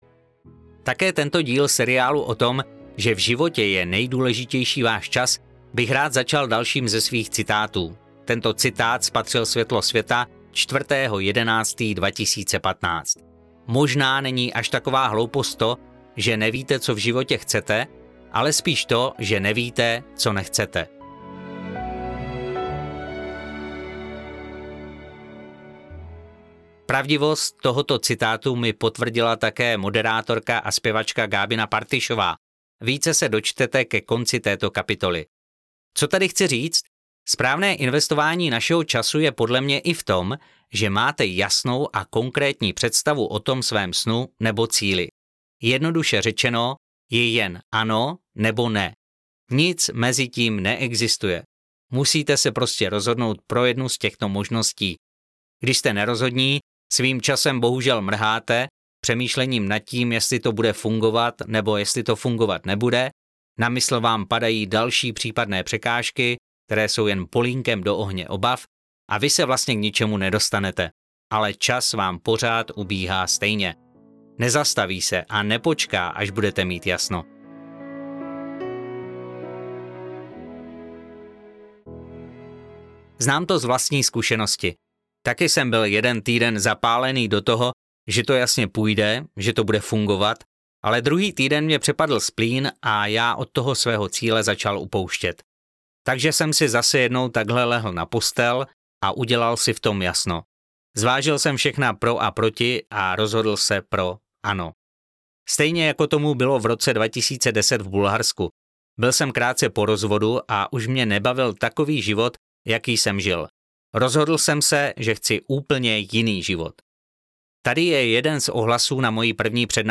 S.O.S. audiokniha
Ukázka z knihy